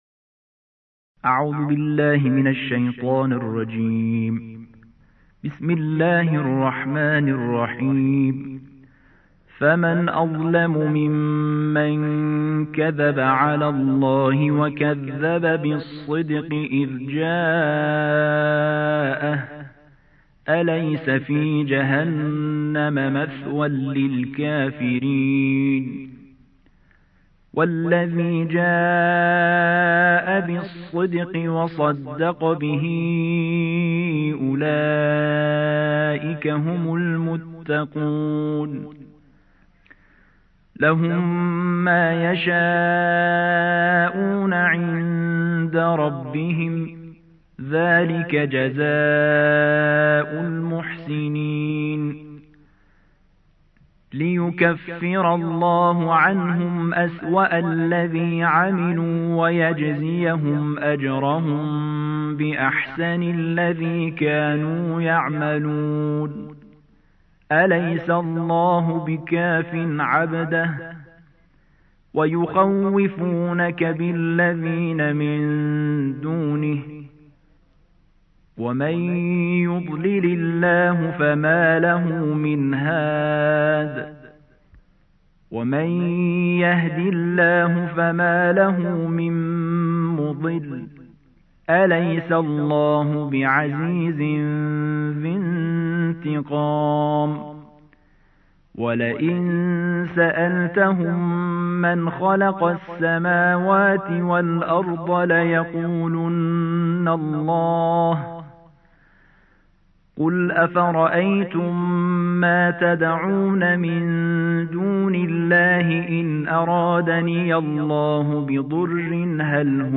الجزء الرابع والعشرون / القارئ